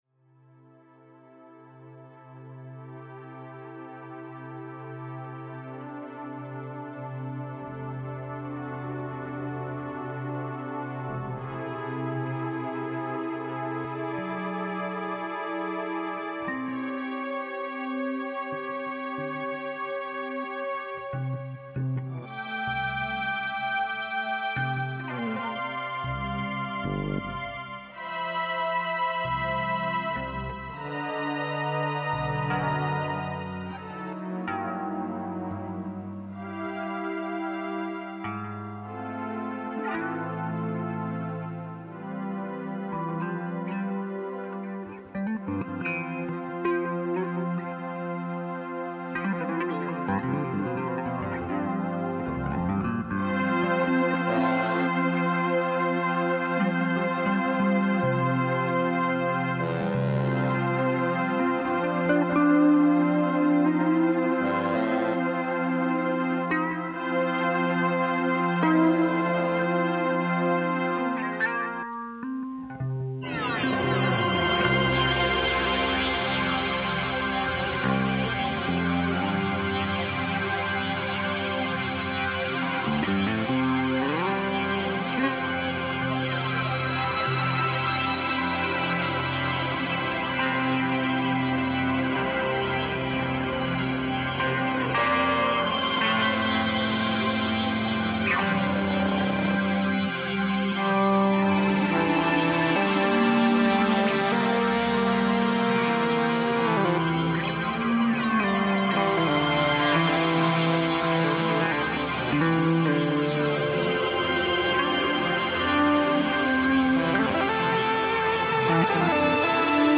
sax and flute
drums and keyboards